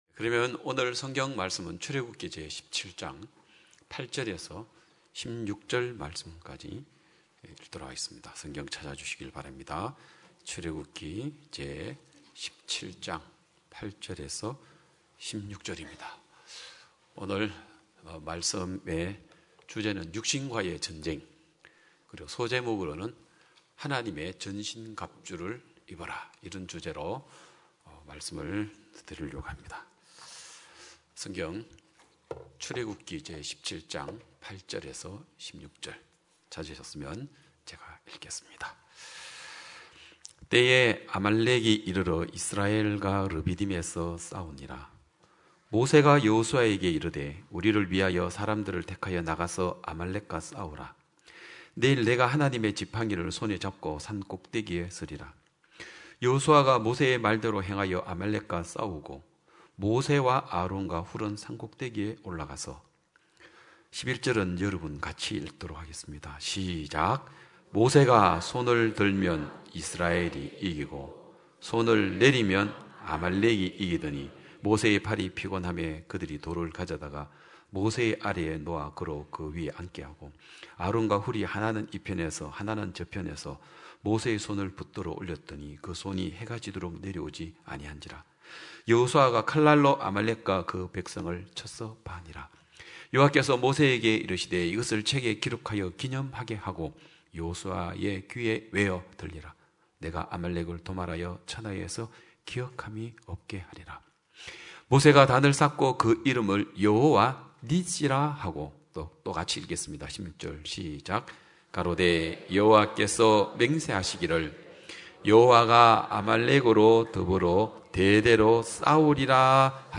성도들이 모두 교회에 모여 말씀을 듣는 주일 예배의 설교는, 한 주간 우리 마음을 채웠던 생각을 내려두고 하나님의 말씀으로 가득 채우는 시간입니다.